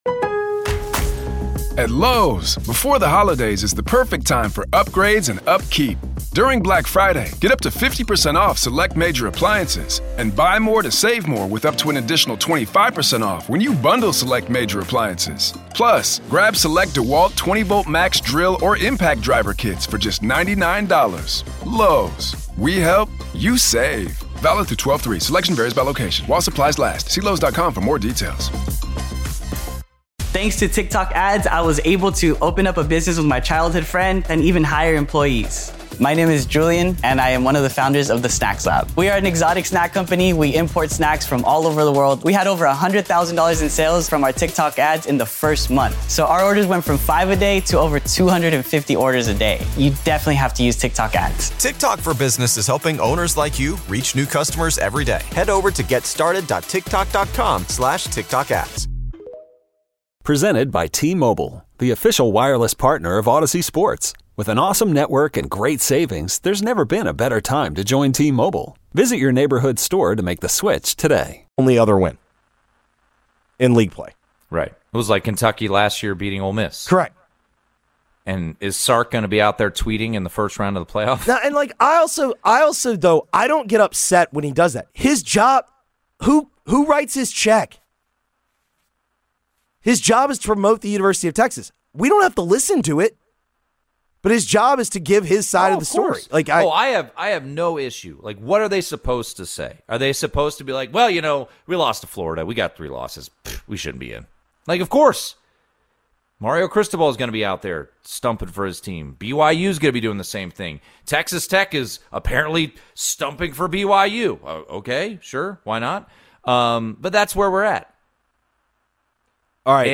live weekdays 2-4PM
now hosts every afternoon with a rotating cast of co-hosts